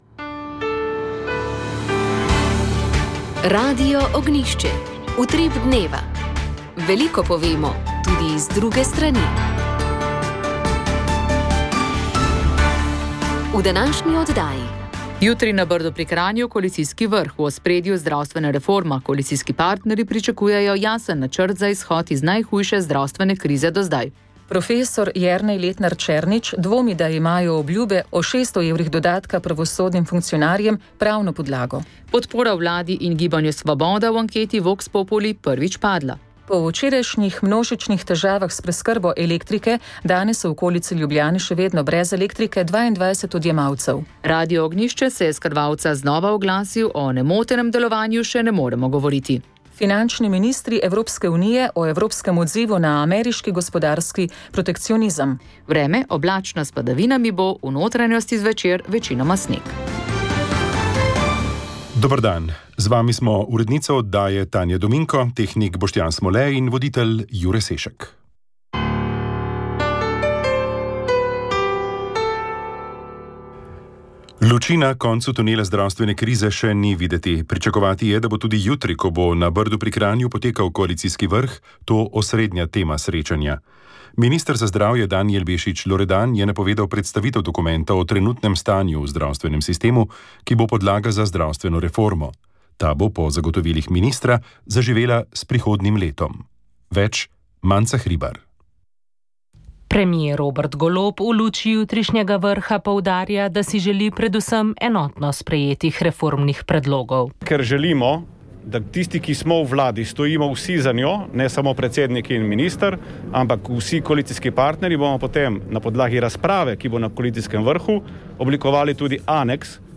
V oddaji Moja zgodba smo objavili še zadnjo peto oddajo z znanstvenega posveta Dachavski procesi – 75 let pozneje. Pripravili sta ga Slovenska matica in Študijski center za narodno spravo sredi novembra lani.